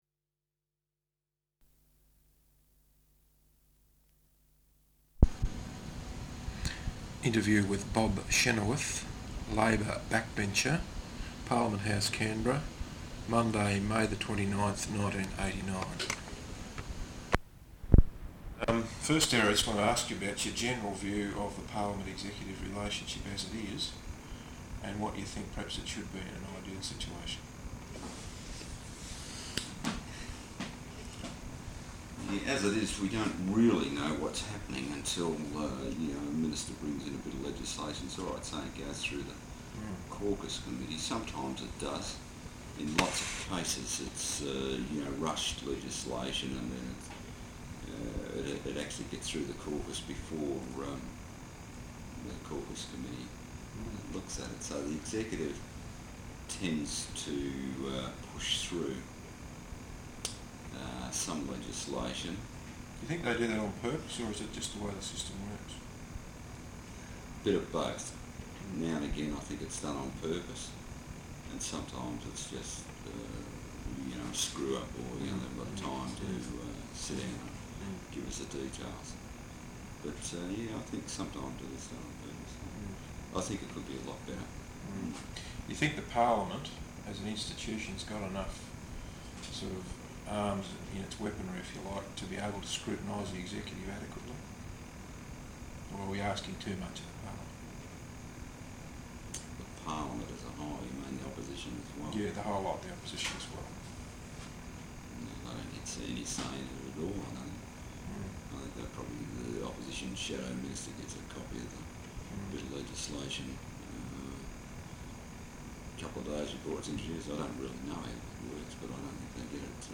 Interview with Bob Chynoweth, Labor Backbencher, at Parliament House, Canberra, on Monday, 29 May, 1989.